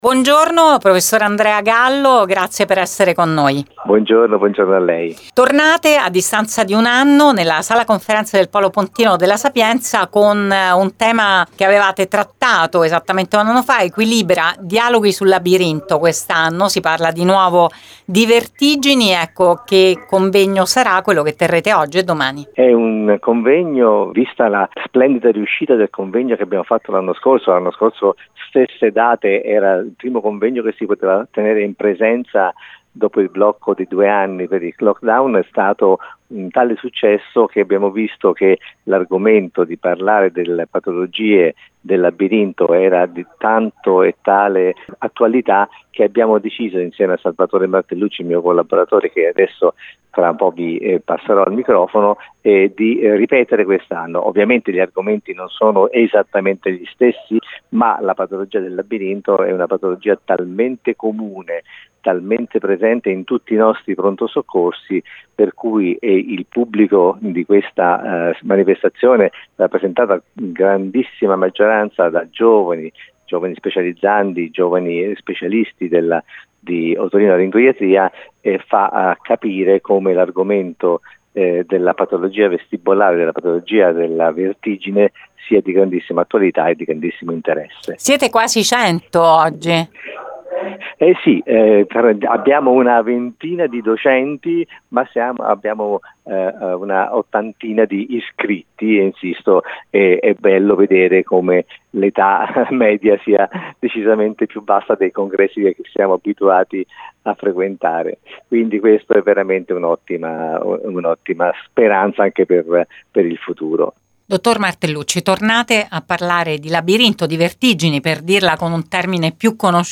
Con noi, in radio